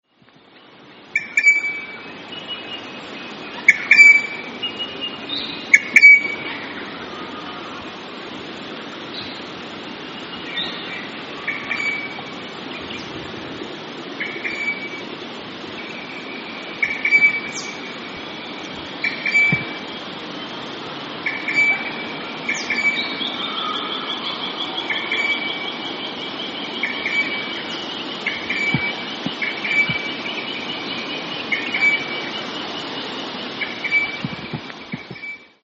Voice: noisy chatter, bell-like call, raucous screech.
Call 1: bell-like call
Adel_Rosella_bell.mp3